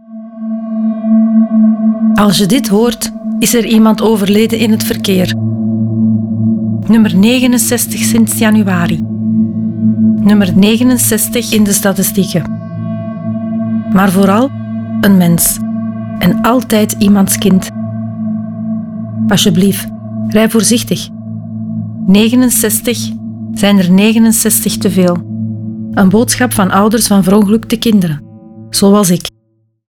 Ouders van Verongelukte Kinderen maken een radiospot voor elk dodelijk verkeersslachtoffer.
De spots werden geproduced door Raygun met de medewerking van échte ouders van verongelukte kinderen, geen acteurs.